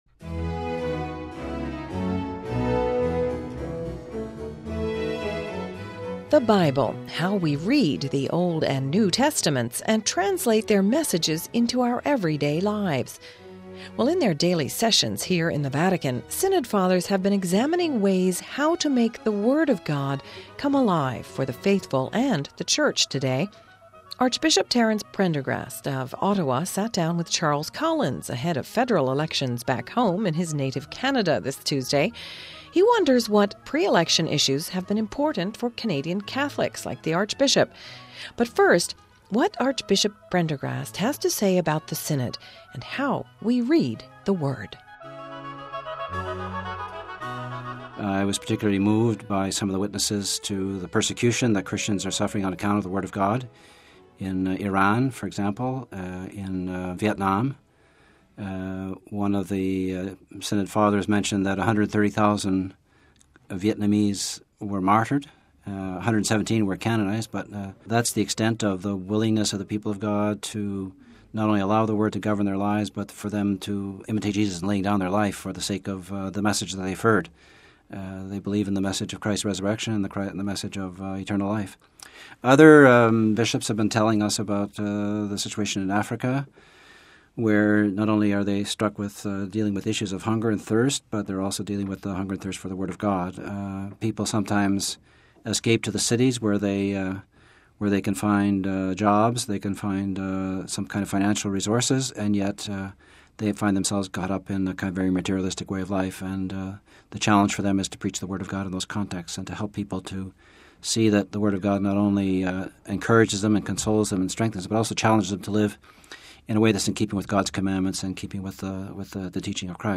Home Archivio 2008-10-14 15:00:18 THE WORD ALIVE We hear one Synod Father and bible expert speak on how to read the Holy Scriptures and apply them in our lives...